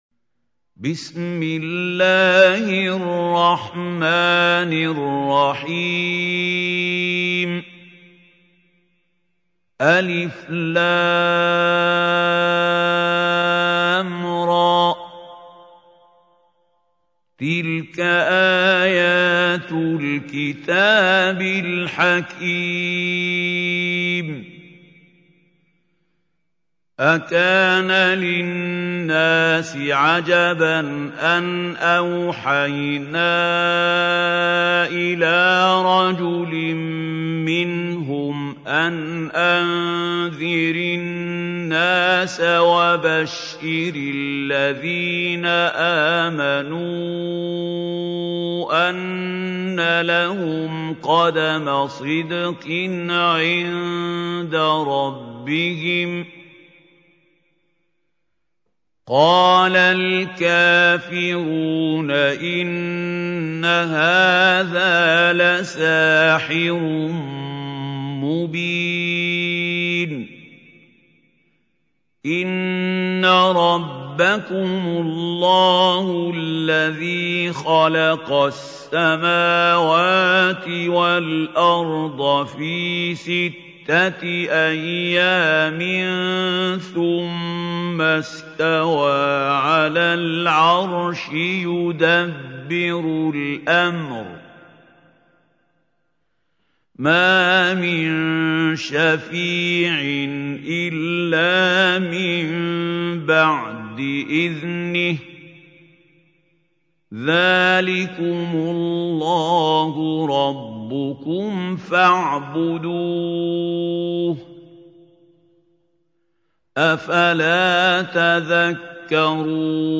Surah Yunus Tilawat by Mahmoud Khalil Al Hussary
Surah Yunus is 10th Chapter of Holy Quran. Listen beautiful tilawat of Surah Yunus in the voice of Mahmoud Khalil Al Hussary.